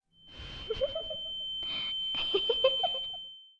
谢幕演出_耳鸣加笑声.wav